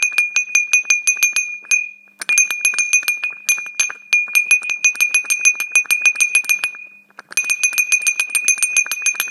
Liatinový zvon retro - hlas ako zvon
Pevný materiál litiny opravdu zaručuje zvonění, jako větší zvon i díky srdci zvonu, které je také z litiny.
Průměr zvonečku 75mm, srdce zvonu litina a výška po hlavičku ptáčka 120mm.